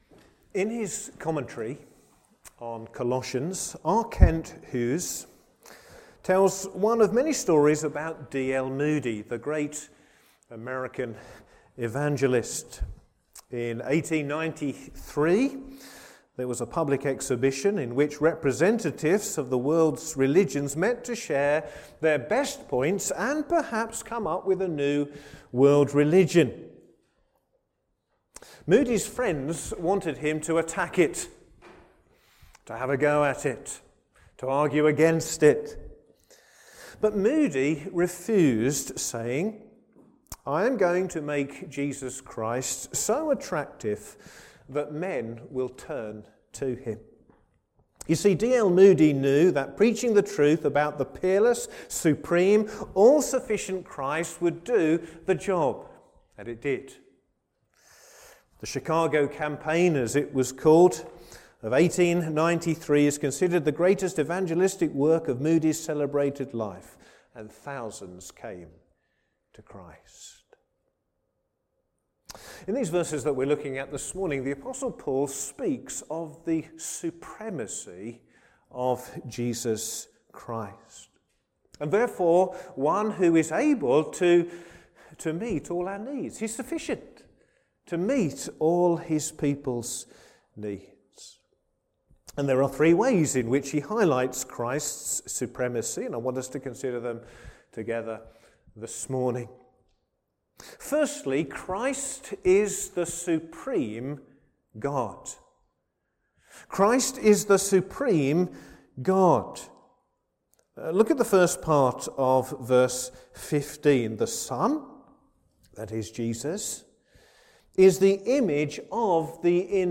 Sermons
Service Morning